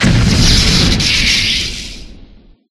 Thunder11.ogg